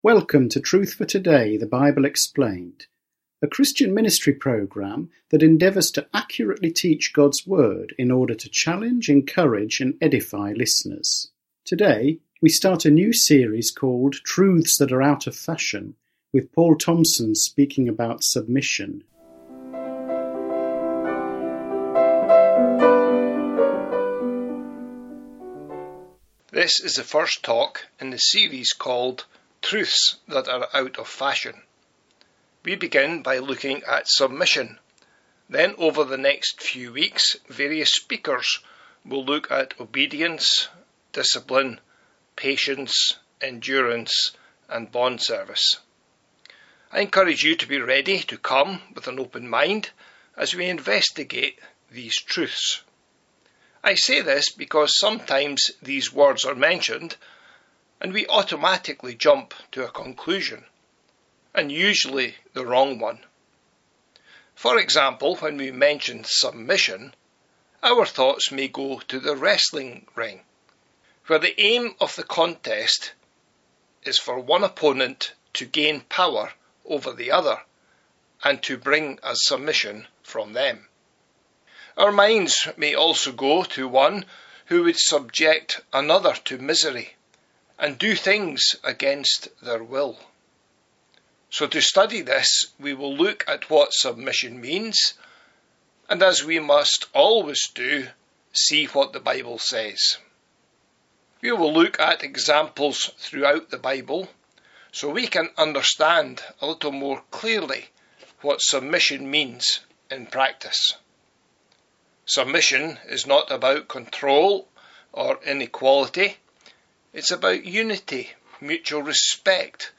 Truth for Today is a weekly Bible teaching radio programme.